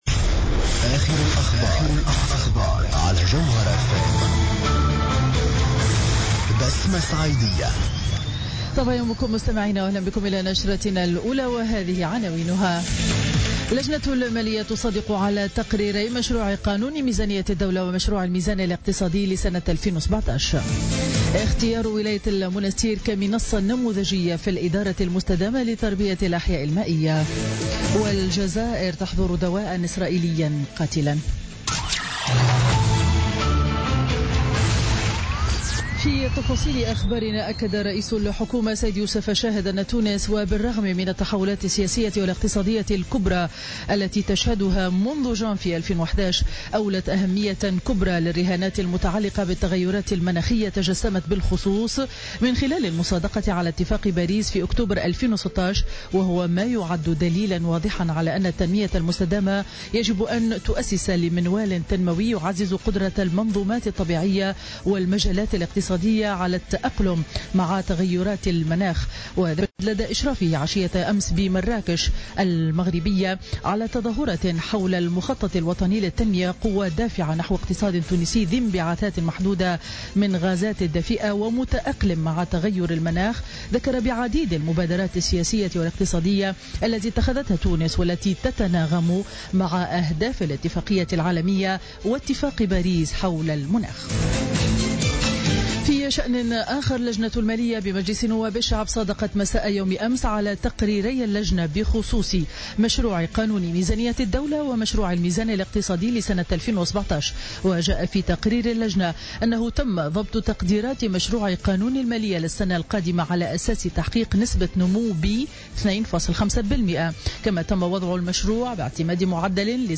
Journal Info 07h00 du mercredi 16 novembre 2016